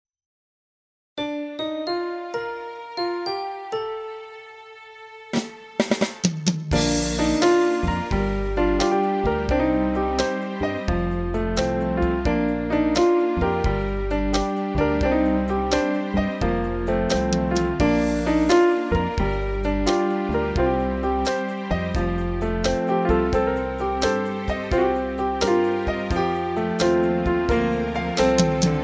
Vocal and Instrumental MP3 Tracks with Printable Lyrics
Instrumental Tracks.